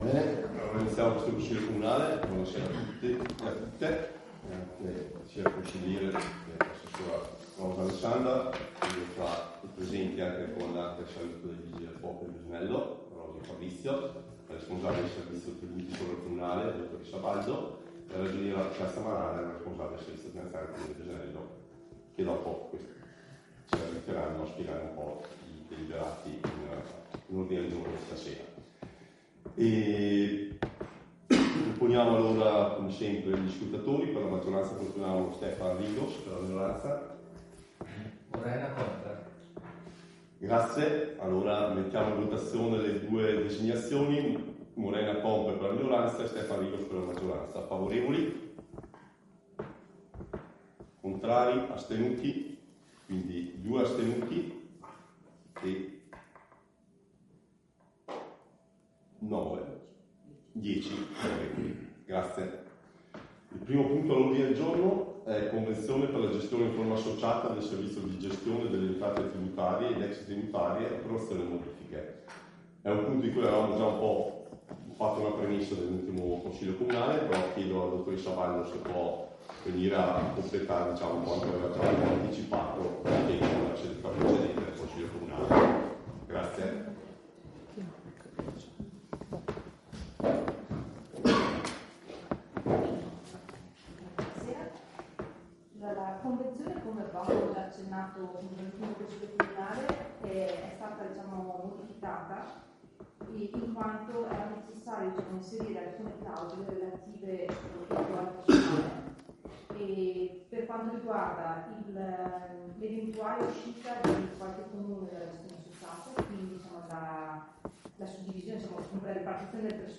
Registrazione Consiglio comunale del 30 dicembre 2024